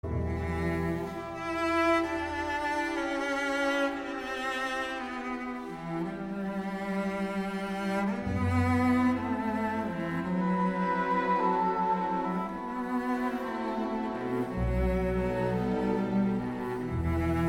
У неё был бархатный голос, глубокий, спокойный и уверенный.
Виолончель
виолончель.mp3